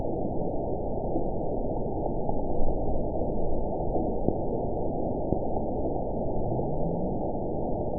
event 922686 date 03/09/25 time 18:46:13 GMT (3 months, 1 week ago) score 9.55 location TSS-AB06 detected by nrw target species NRW annotations +NRW Spectrogram: Frequency (kHz) vs. Time (s) audio not available .wav